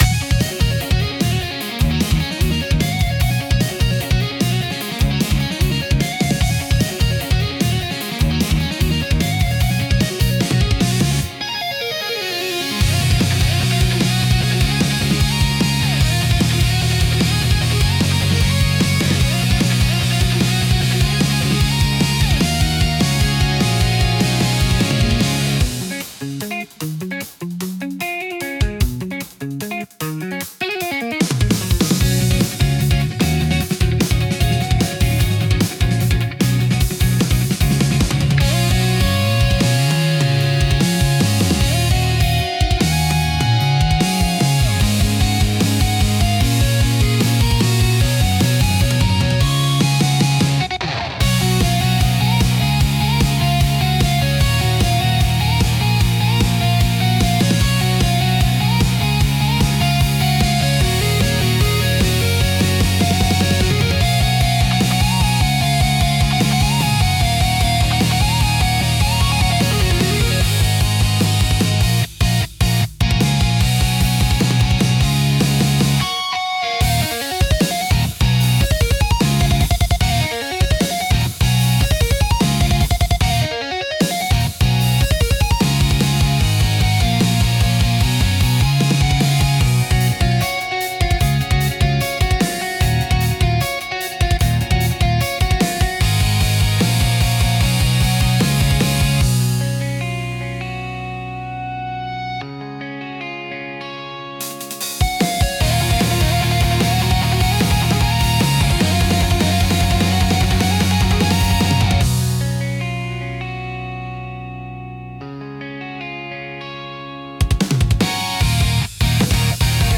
テンポの速いリズムと洗練されたサウンドで、スリルと爽快感を演出し、没入感を促進します。